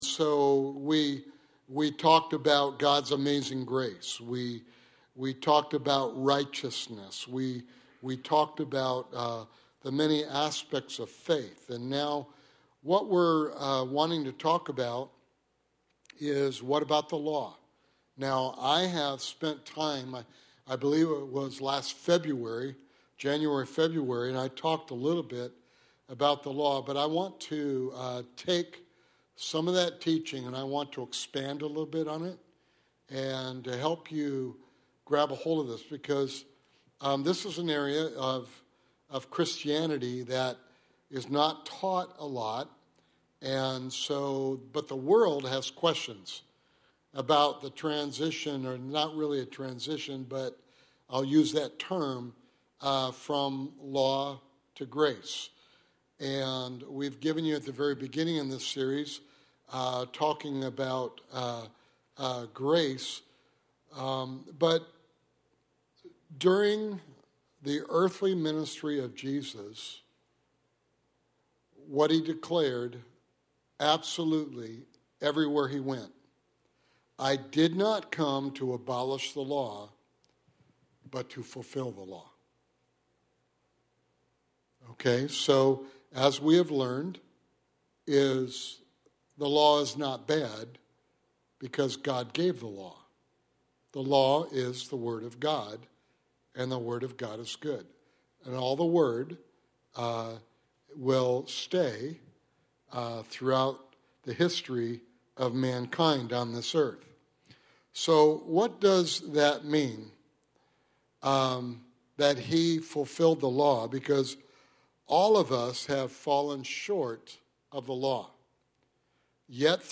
Sermon Series: The Plan of God (Bible Study)